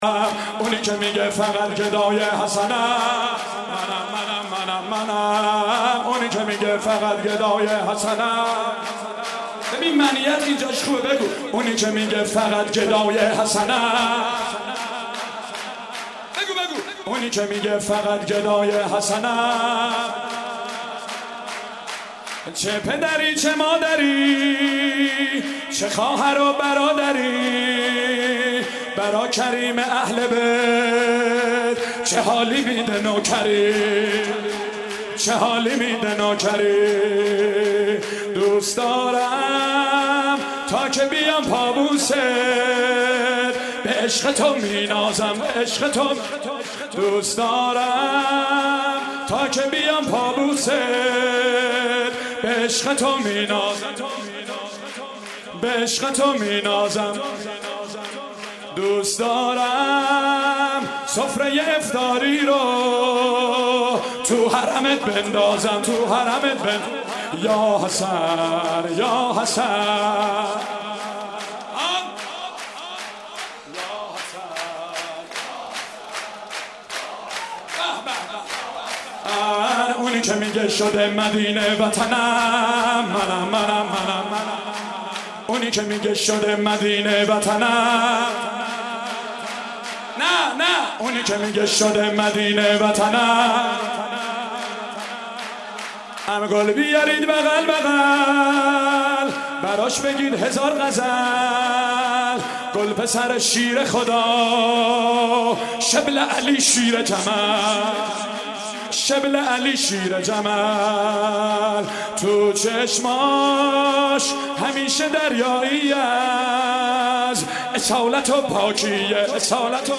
مراسم جشن میلاد امام حسن مجتبی با مداحی
مدیحه سرائی ، سرود